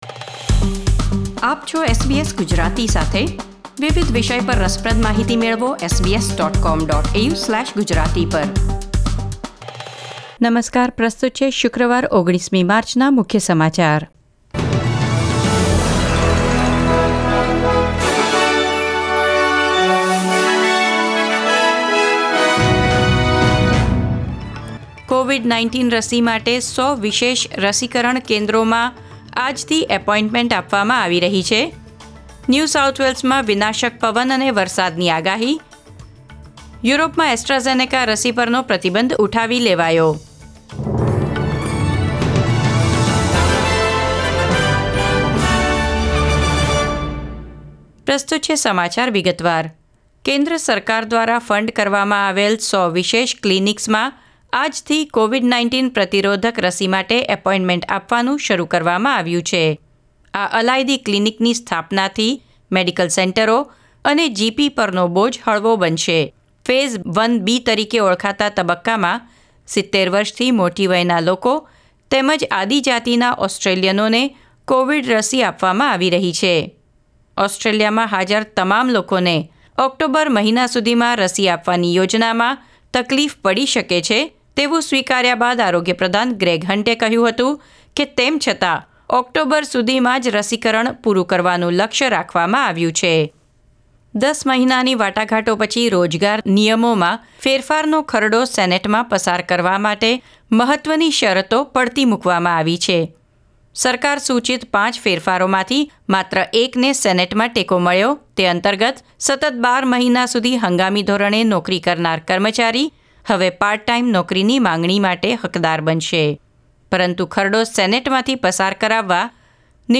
SBS Gujarati News Bulletin 19 March 2021